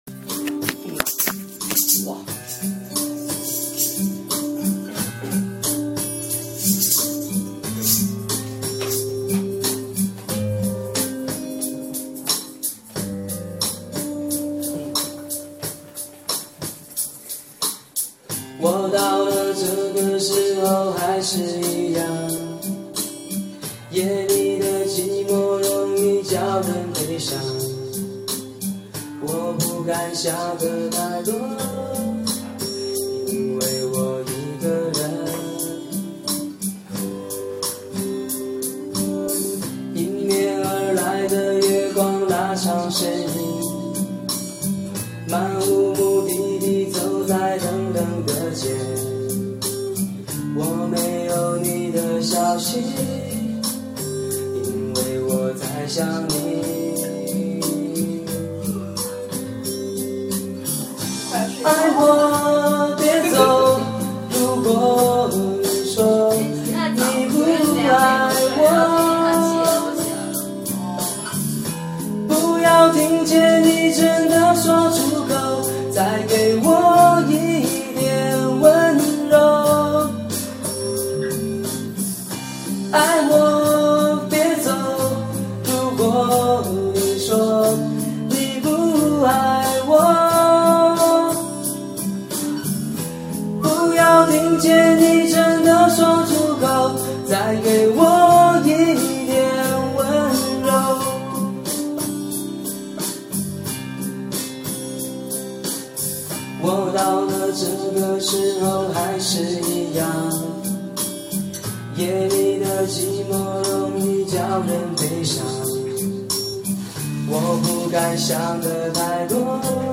PS：我唱歌时，身旁有人说话。。。请各位当作没听见，还录了好多，不知道有没有勇气陆续发上来:cn09:
唱的还是不错的哈，而且KTV里录制还没有爆音，感觉很好哈~~~
偶尔有些飘，但是很不错啦，比我好，哈哈